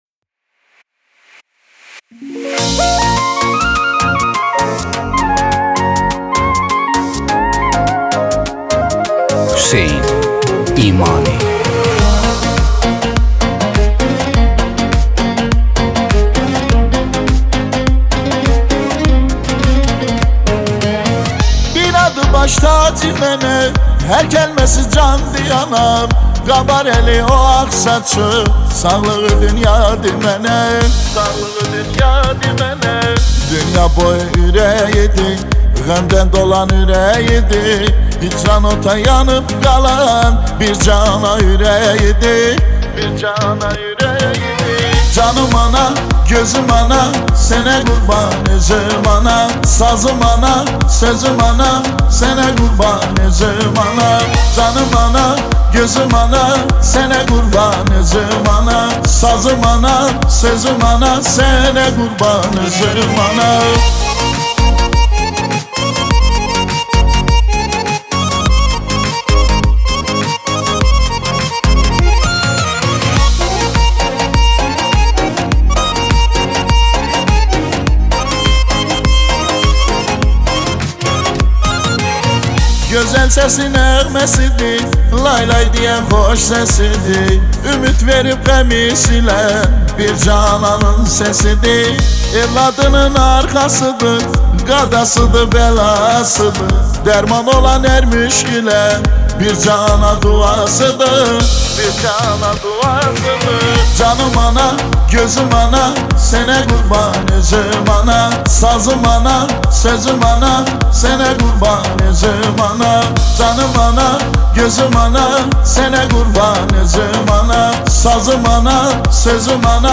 دانلود آهنگ محلی ترکی
اهنگ محلی شاد برای مادر